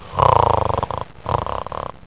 Man Snore Sound Effect Free Download
Man Snore